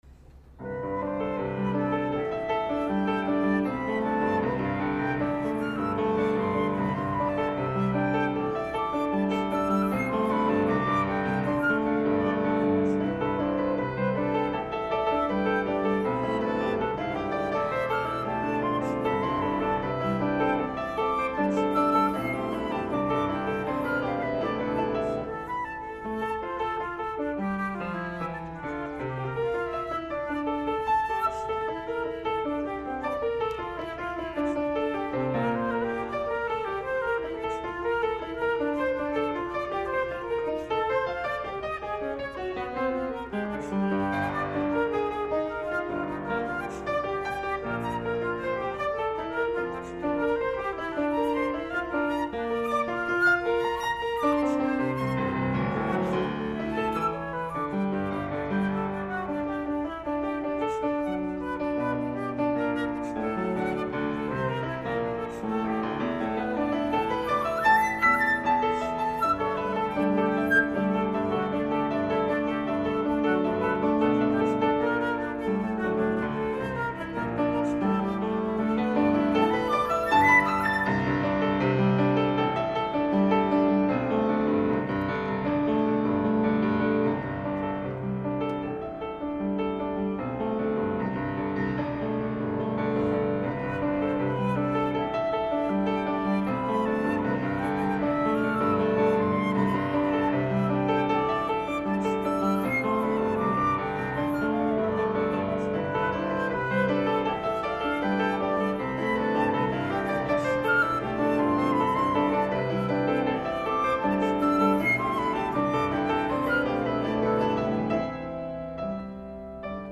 World Premiere
Flute
Piano Concert 2004